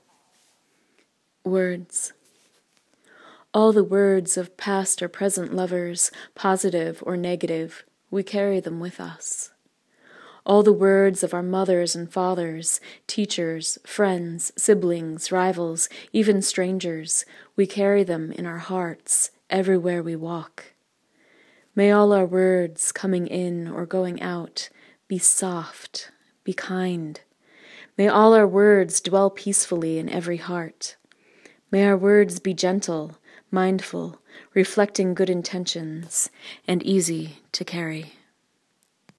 listen to her read “